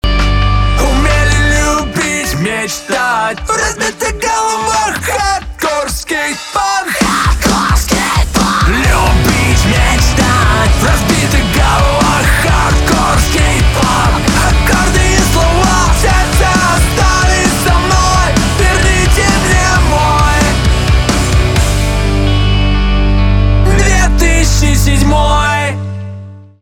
русский рок , гитара , барабаны
громкие